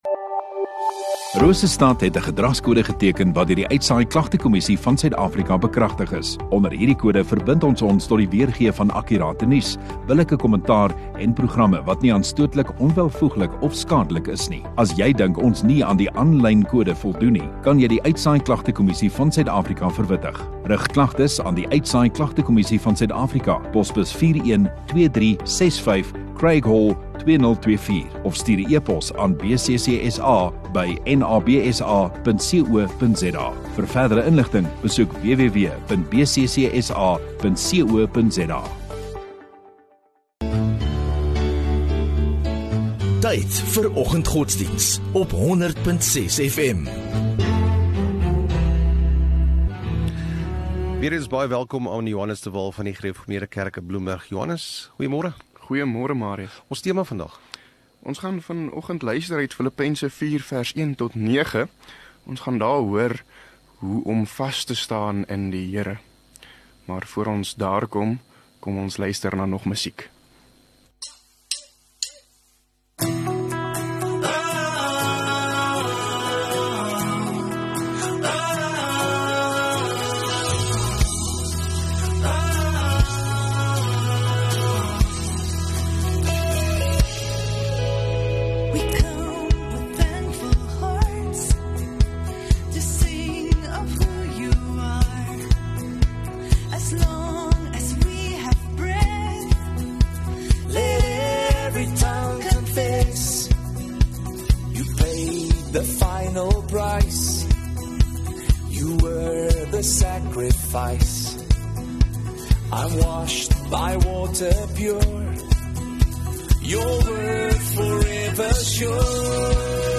2 Aug Vrydag Oggenddiens